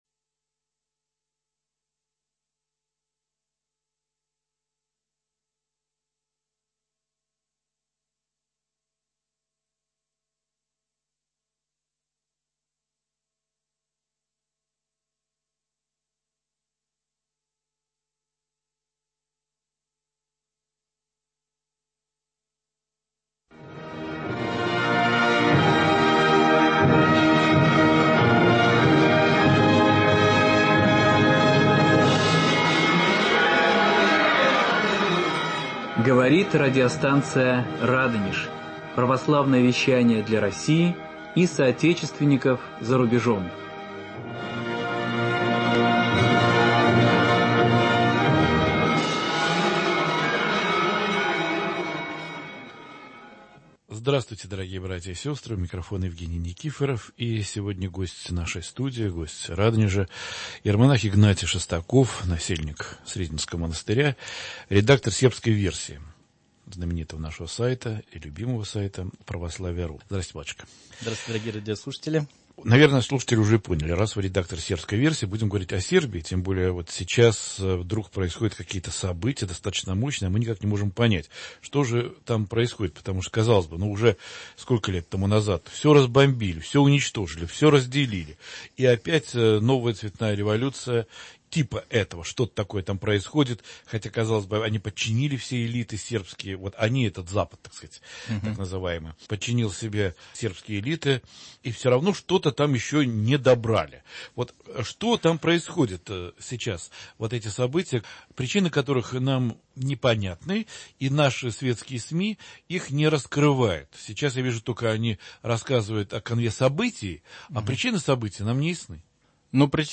К 20-летию НАТО-вских бомбардировок Югославии: беседа директора Радио